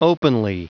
Prononciation du mot openly en anglais (fichier audio)
Prononciation du mot : openly